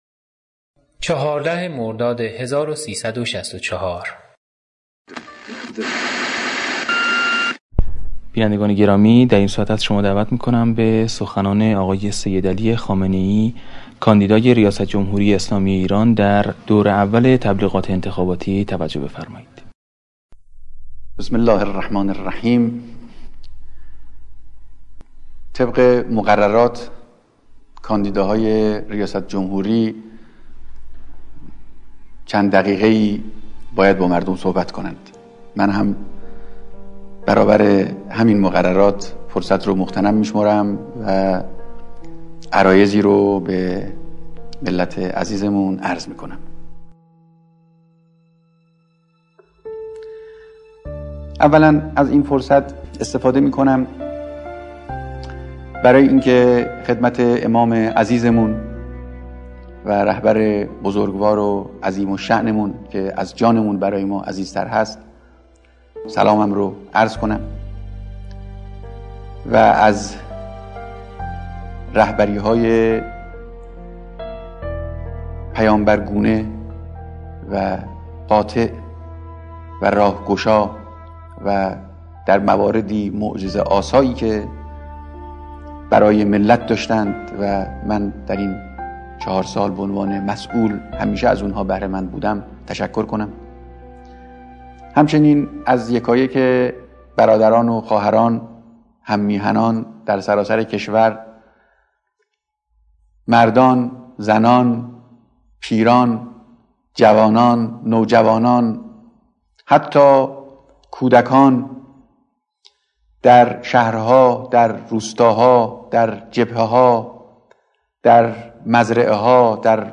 notghe-entekhabati-ayatollah-khamenei.mp3